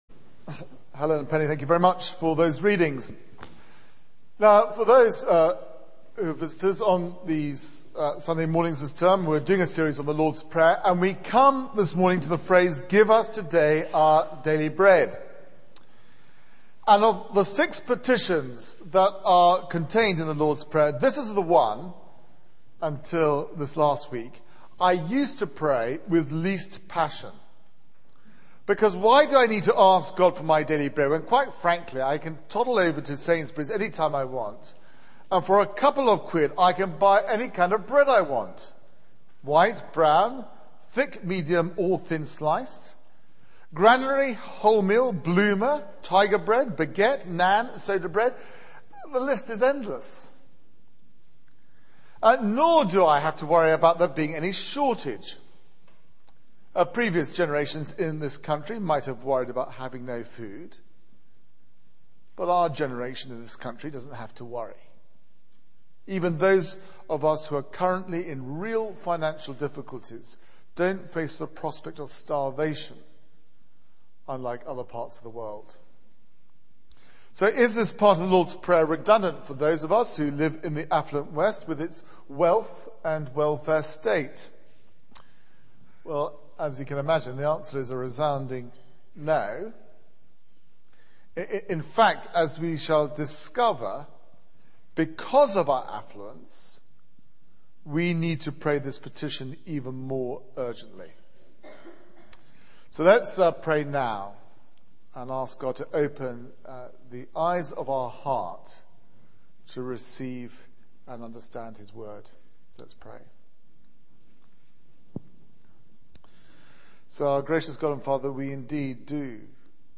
Media for 9:15am Service on Sun 17th Oct 2010
Theme: 'Give us today our daily bread' Sermon